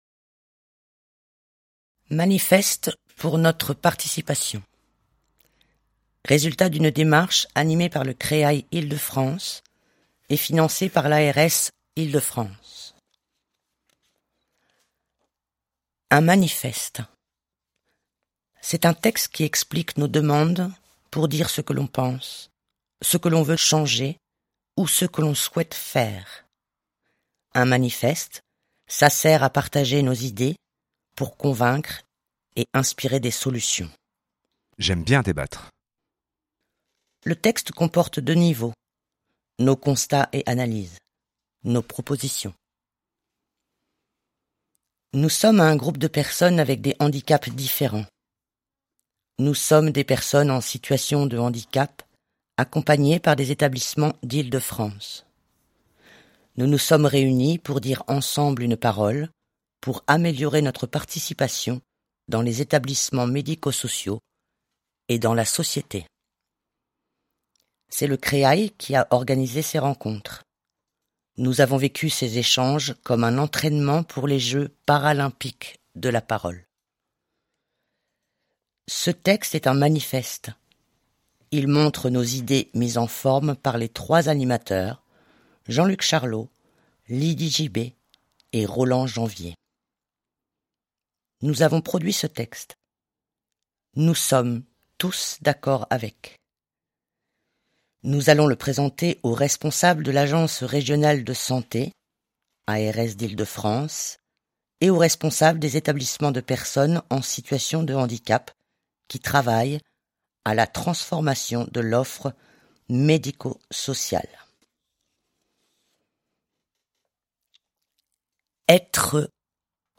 Lecture du manifeste Notre participation, mp3, nouvelle fenêtre